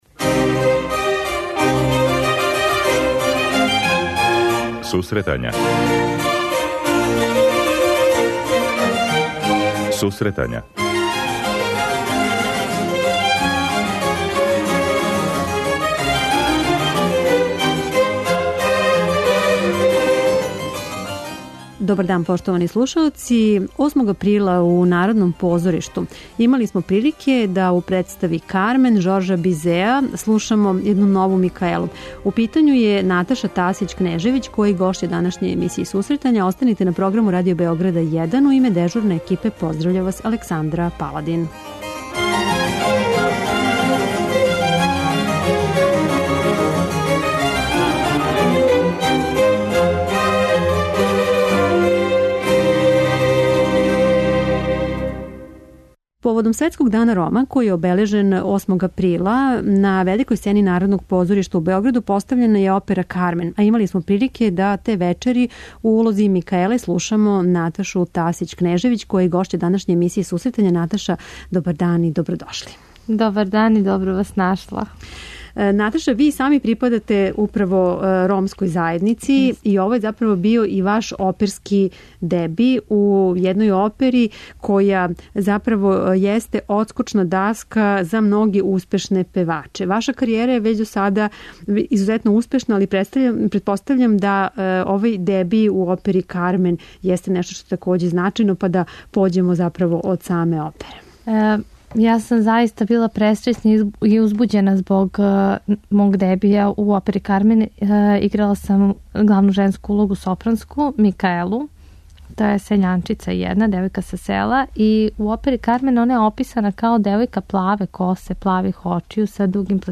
Ова уметница, која има веома успешну каријеру, гошћа је данашње емисије.